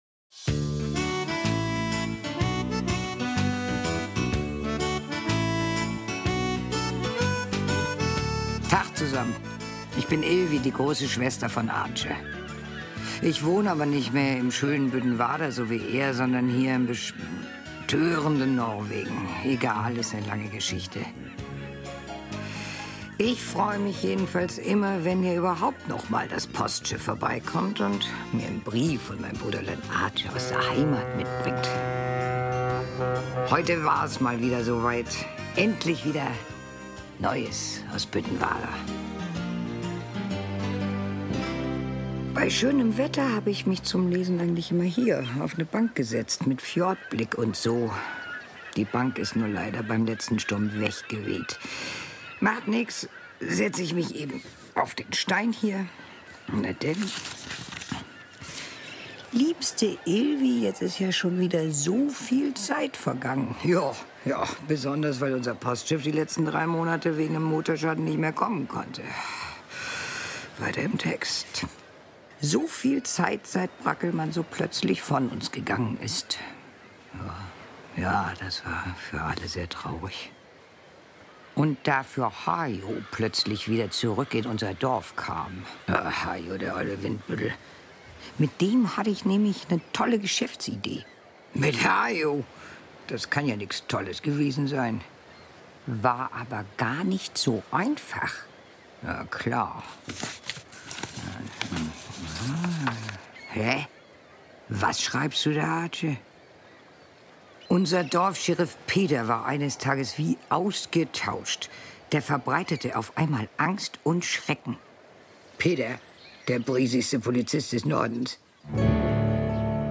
Büttenwarder-Hörspiel: Schnupfi ~ Neues aus Büttenwarder Podcast